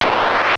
There are four different sounds produced by the game.
QSDefender_PlayerMissile.wav